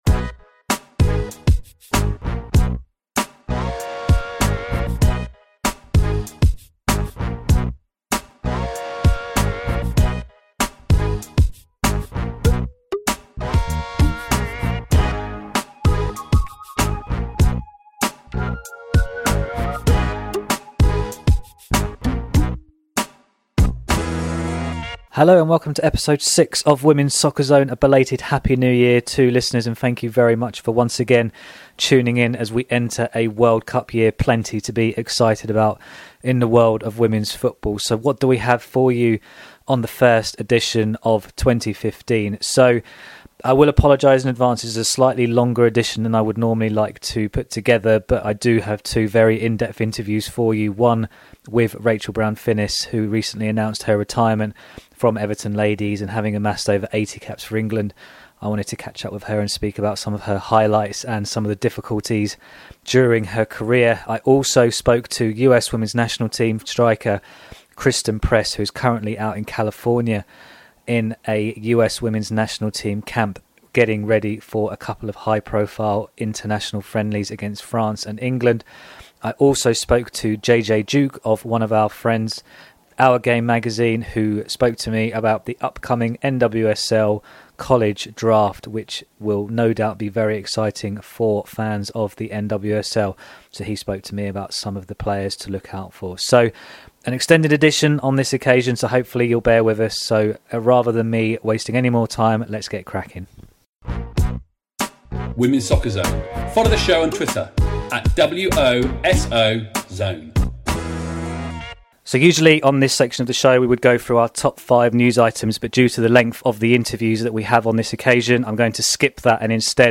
For the first edition of 2015 we bring you an interview with Everton's Rachel Brown-Finnis after she announced her retirement recently.